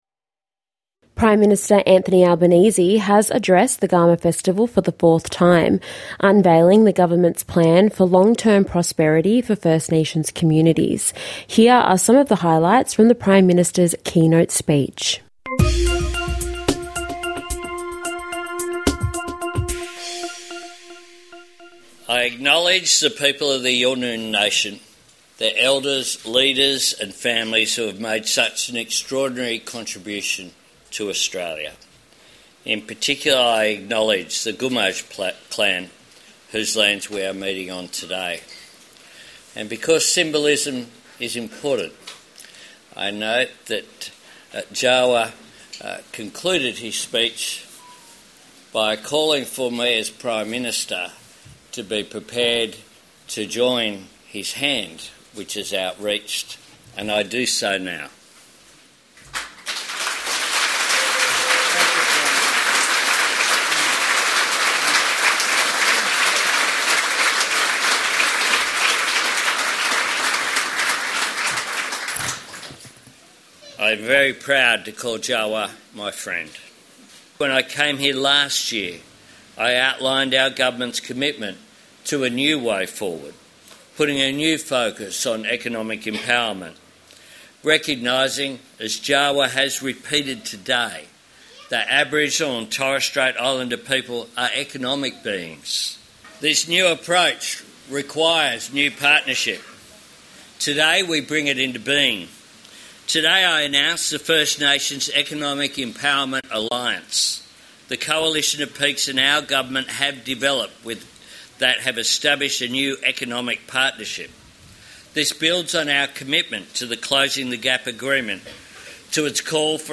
Prime Minister Anthony Albanese speaks during the 25th annual Garma Festival in Gulkula, Northern Territory.
Here are some highlights from the Prime Minister’s keynote speech on Saturday.